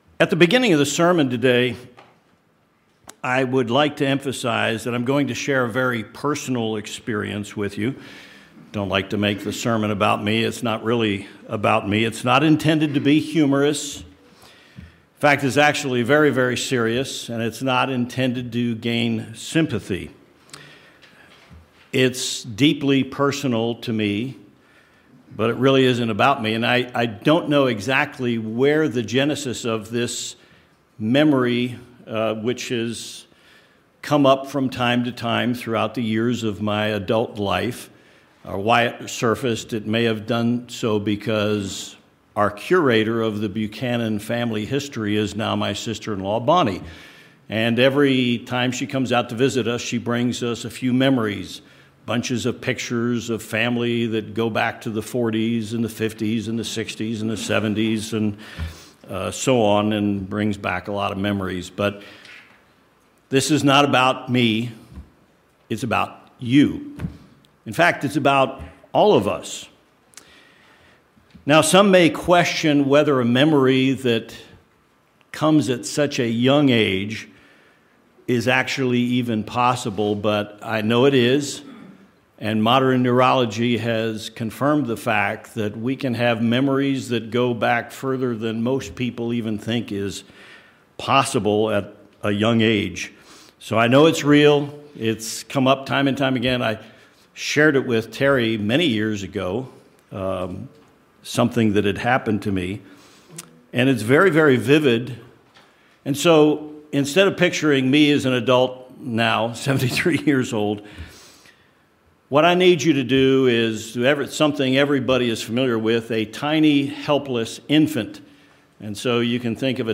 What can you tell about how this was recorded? Given in Tucson, AZ El Paso, TX